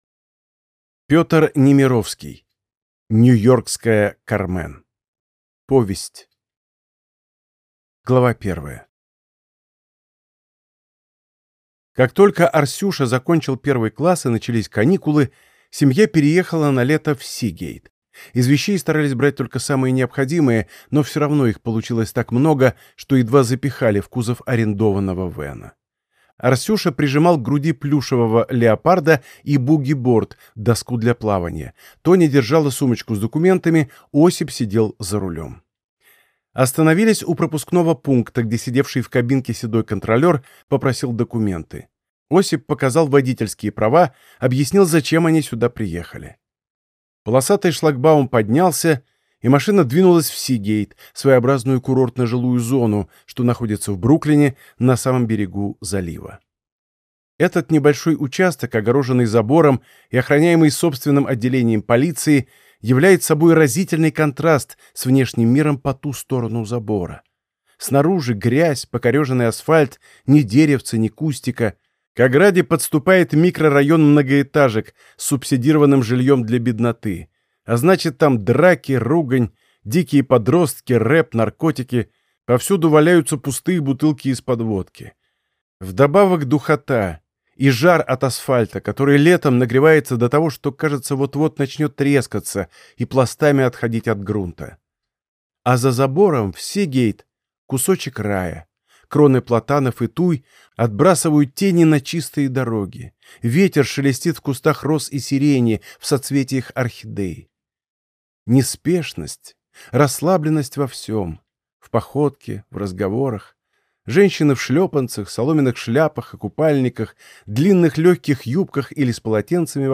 Аудиокнига Нью-йоркская Кармен | Библиотека аудиокниг